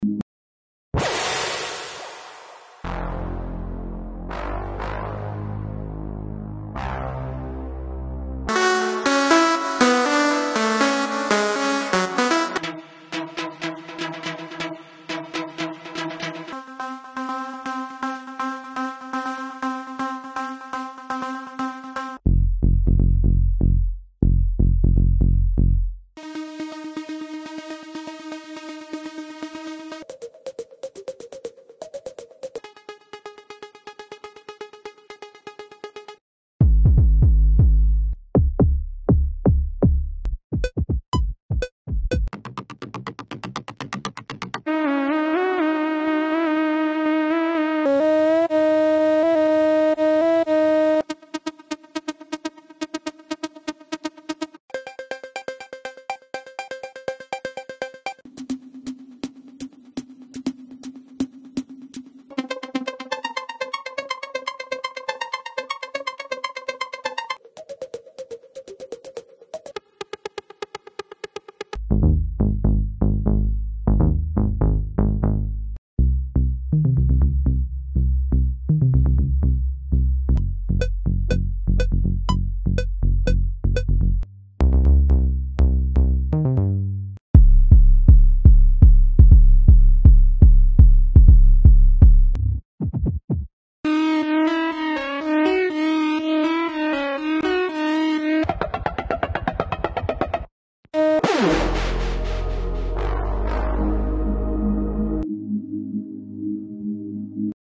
Arpeggios
Deep Basses
FX Whoops
Plucks
Leads